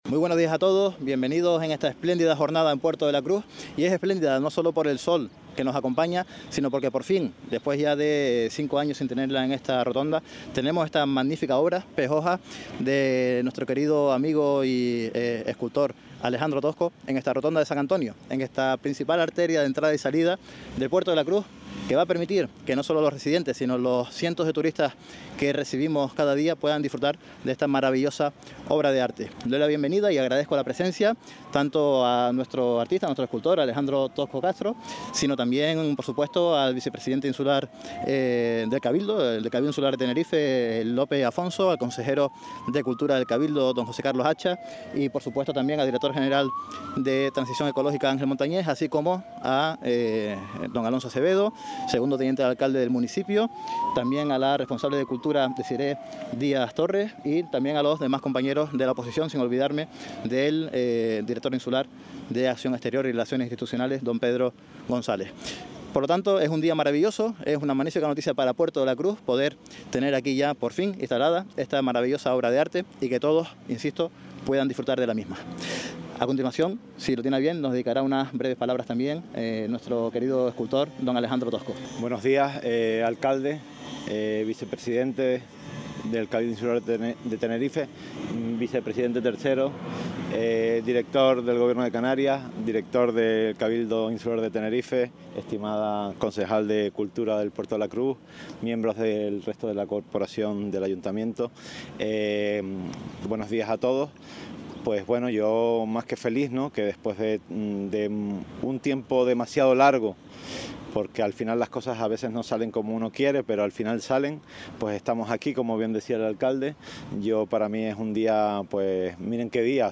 Acto y entrevistas en programa ‘ Las mañanas’